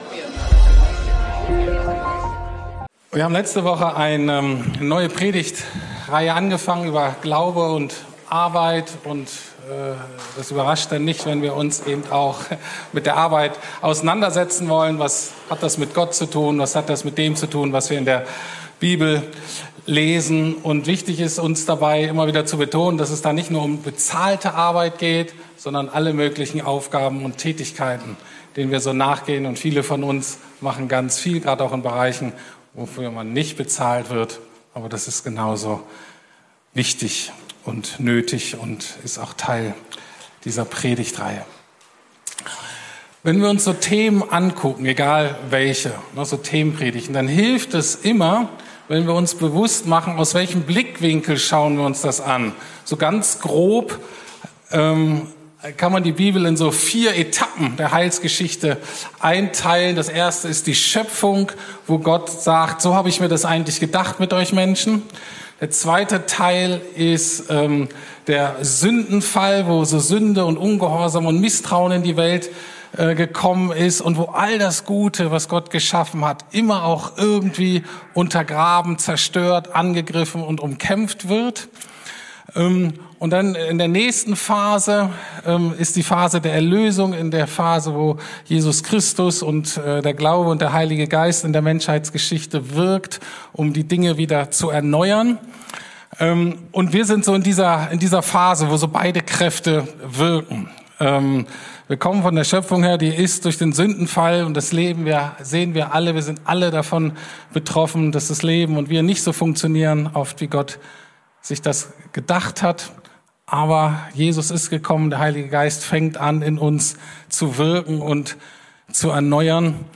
Glaube und Arbeit: Unsere Kämpe mit der Arbeit ~ Predigten der LUKAS GEMEINDE Podcast